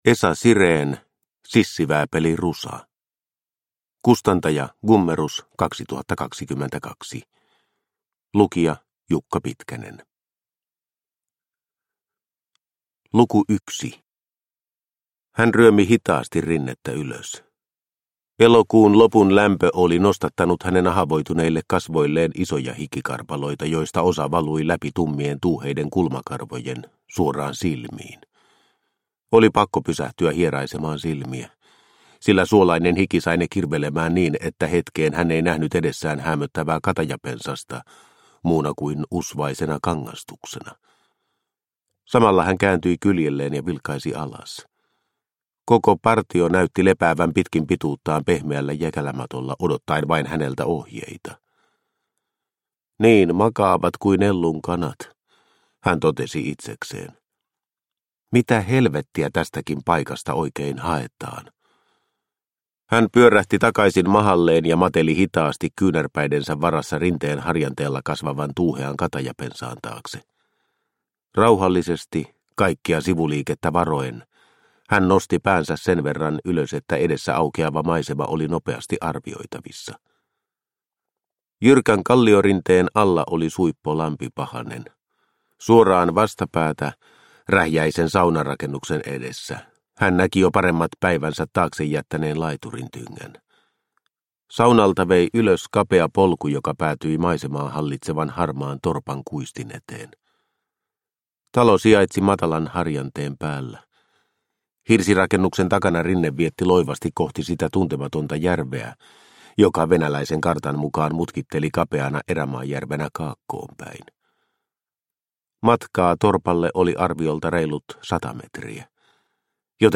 Sissivääpeli Rusa – Ljudbok – Laddas ner
Uppläsare: